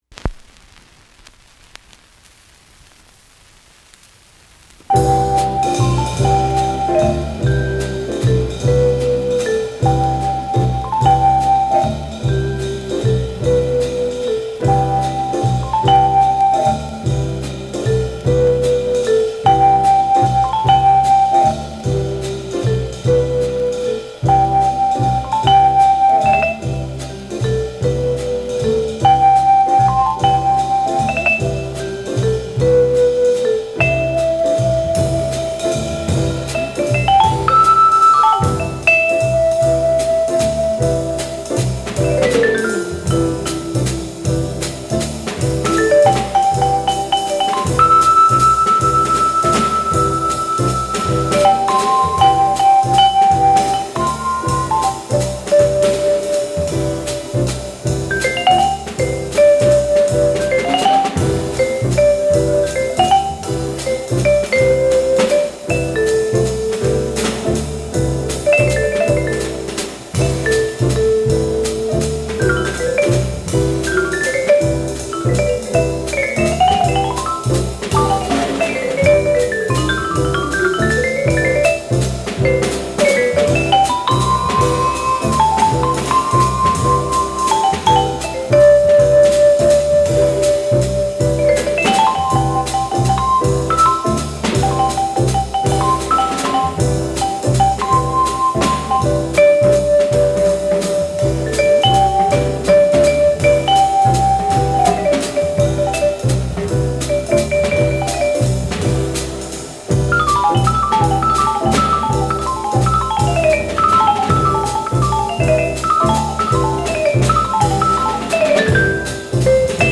Second mono pressing ca. 1962